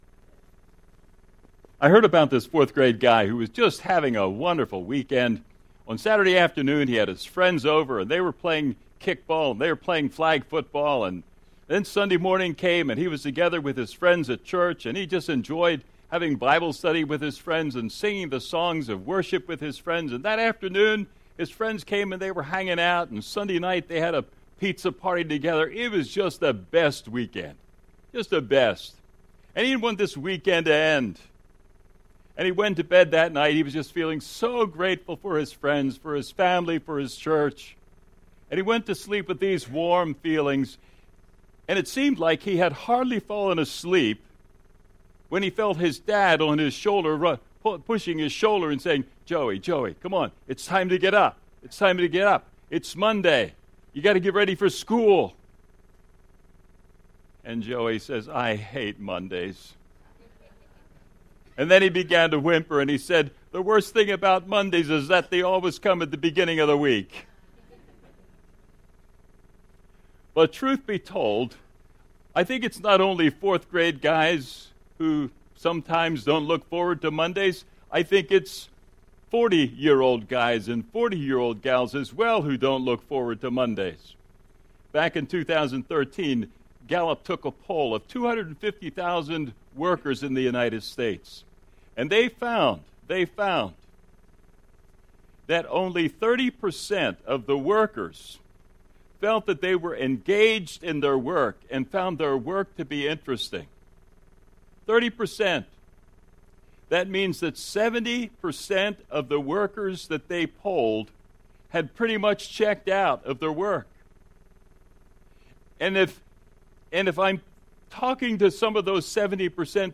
Sermon Series Messages